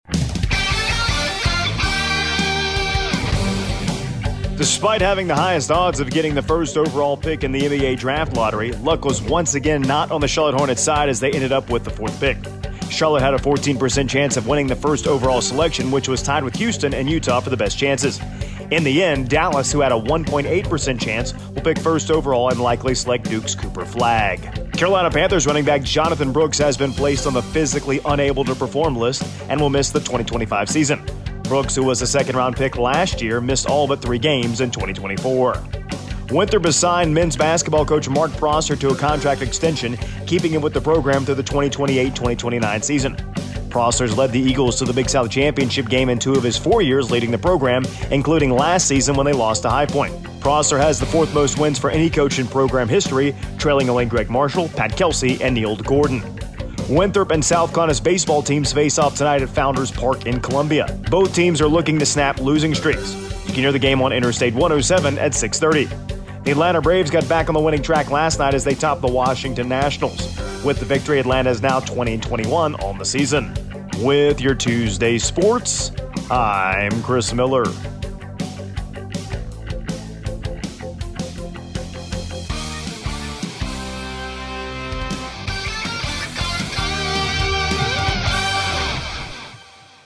AUDIO: Friday Morning Sports Report